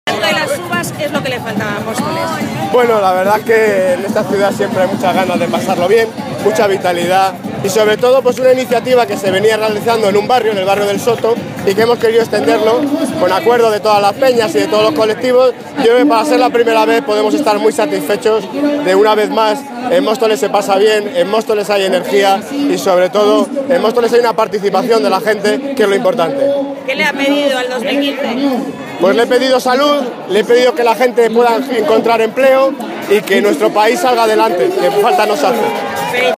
Audio del Alcalde de Móstoles, Daniel Ortiz.